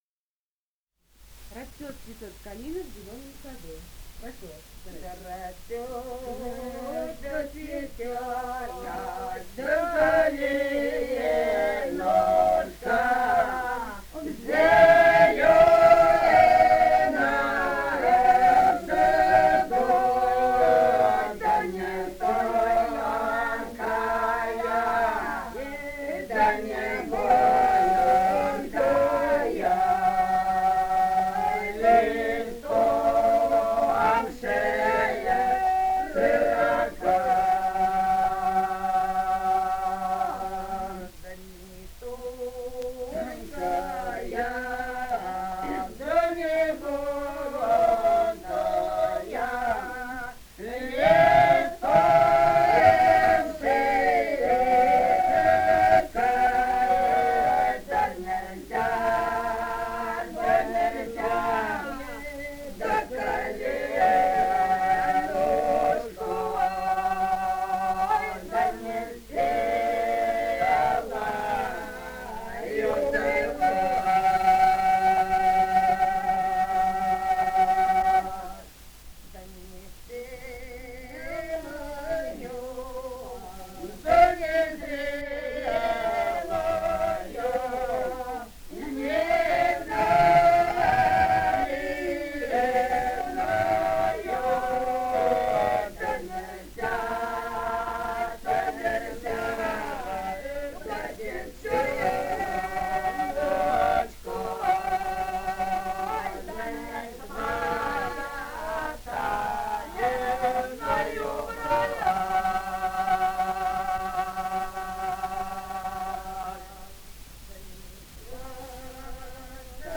Этномузыкологические исследования и полевые материалы
Ростовская область, ст. Вёшенская, 1966 г. И0940-03